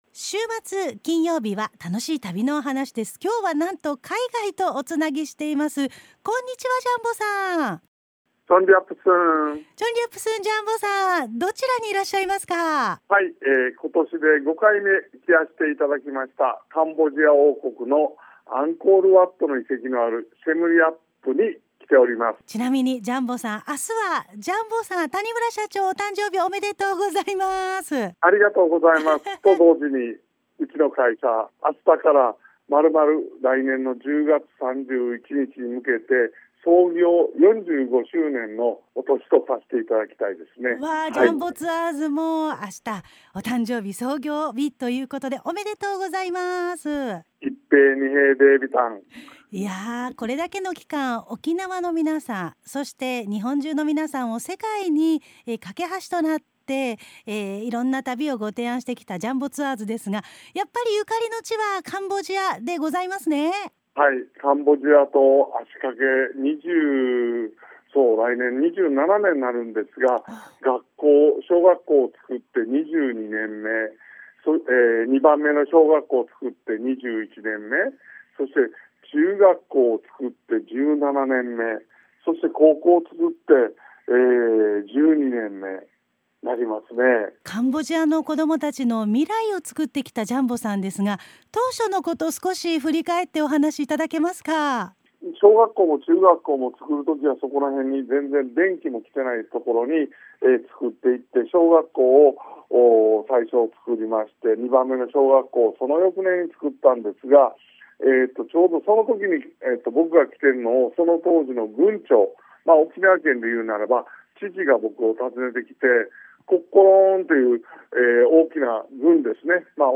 ★『カンボジア／沖縄ゆいまーる学校／カンボジアへ会社設立』2025年10月31日(金)ラジオ放送